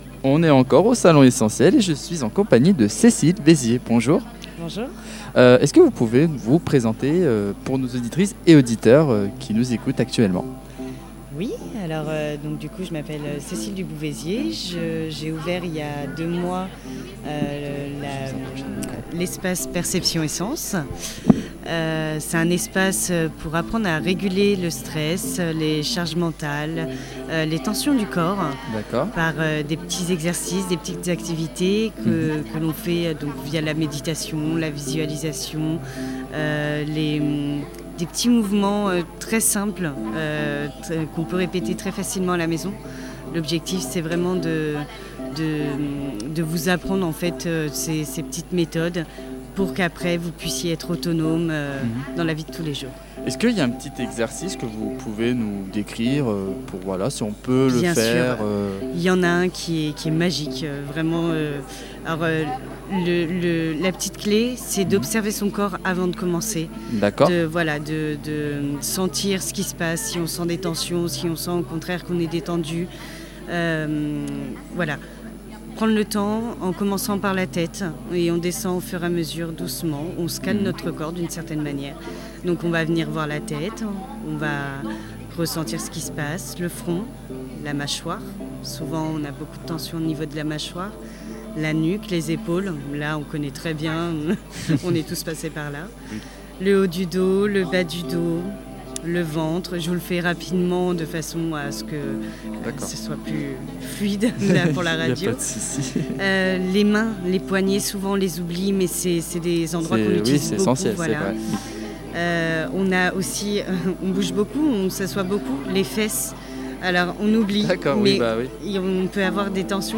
Les interviews du Salon Essenti’Elles – Édition 2026
À l’occasion du Salon Essenti’Elles, organisé les 7 et 8 mars 2026 au gymnase de Châtillon-Coligny, l’équipe de Studio 45 est allée à la rencontre des organisatrices et des nombreux exposants présents durant ce week-end consacré au bien-être et à l’univers féminin.